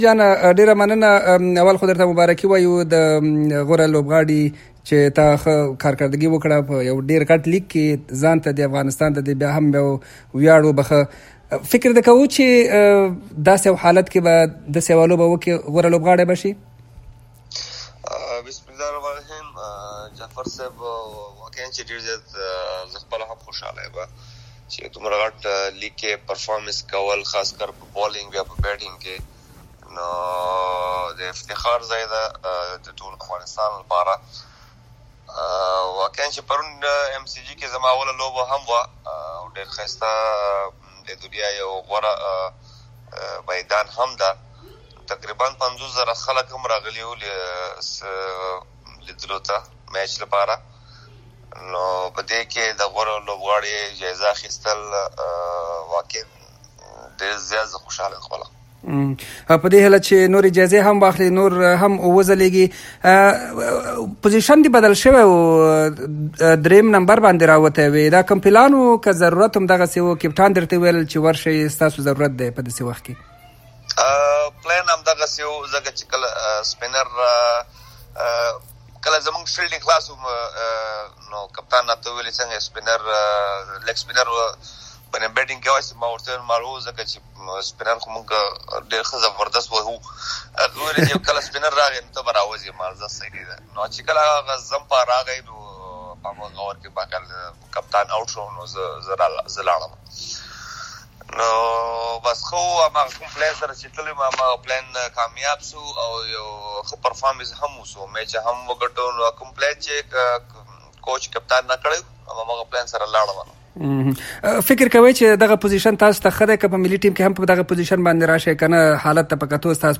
له محمد نبي سره مرکه